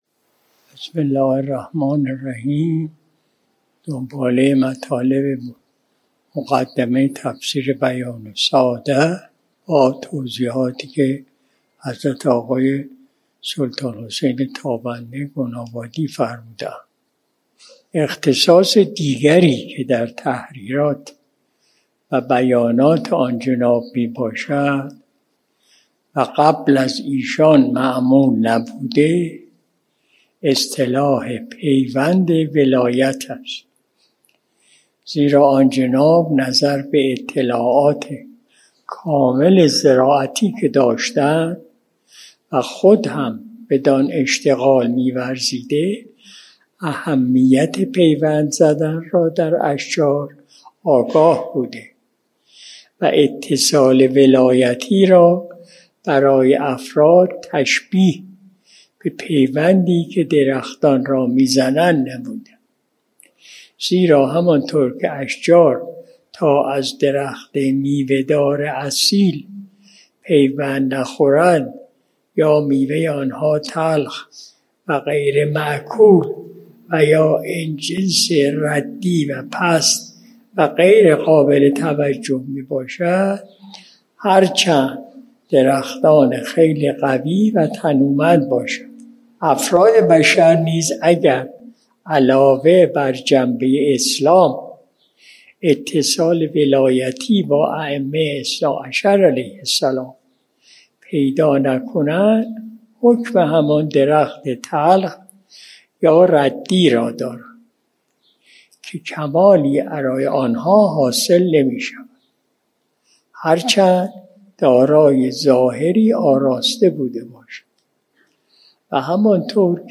مجلس صبح جمعه ۲۶ خرداد ماه ۱۴۰۲ شمسی